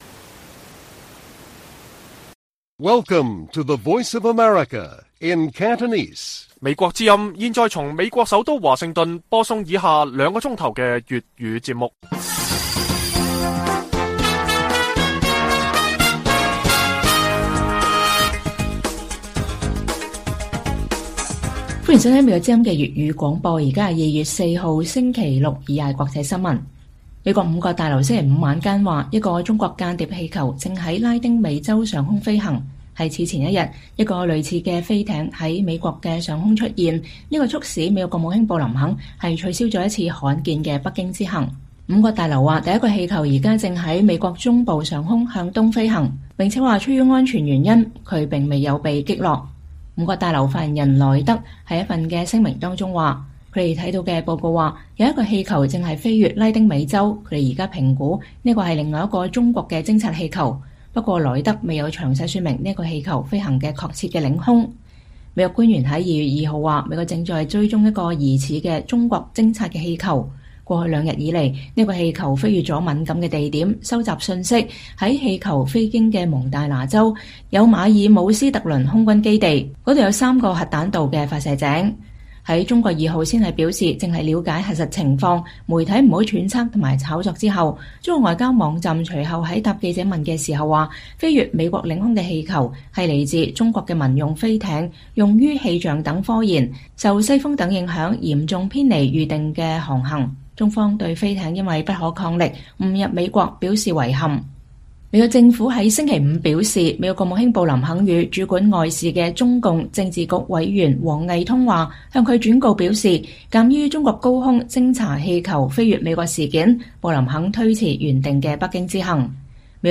粵語新聞 晚上9-10點：五角大樓：第二個中國氣球正“飛越”拉丁美洲